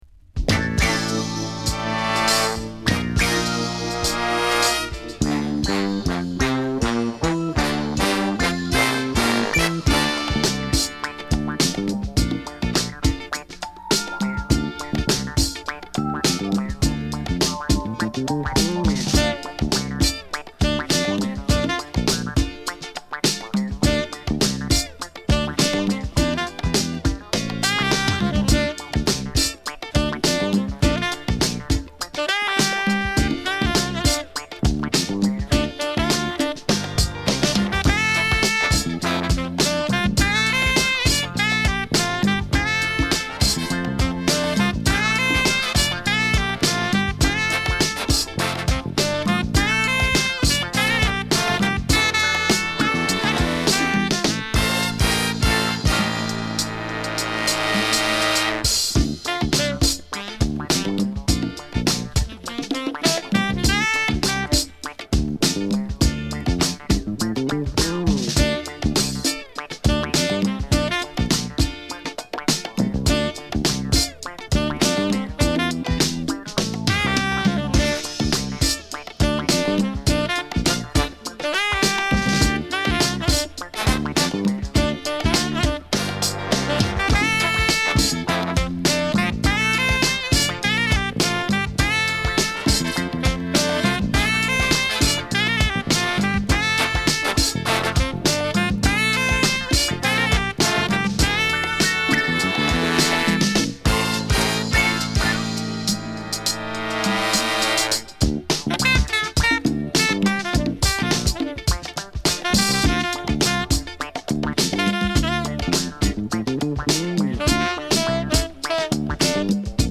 フュージョンテイストを持った心地良い曲を満載！